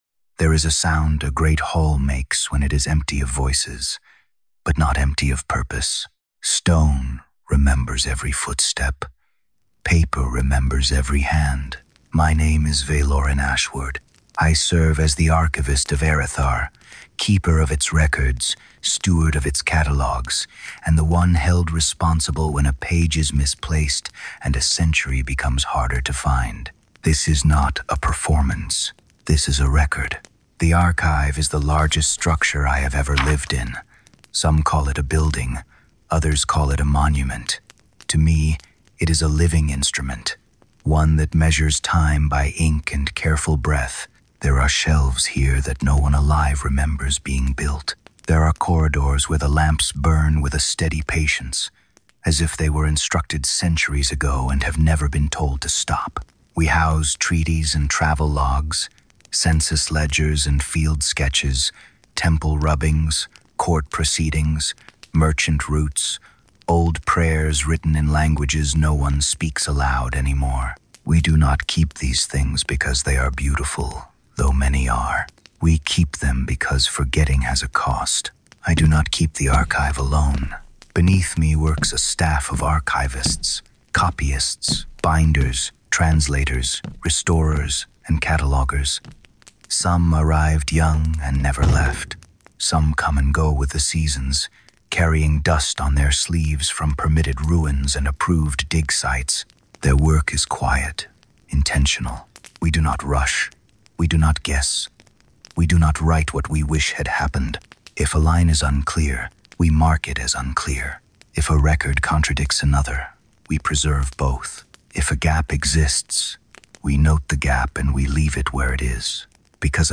The tone is calm, reflective, and orderly, with the first faint suggestion that the history Vaelorin protects may be incomplete.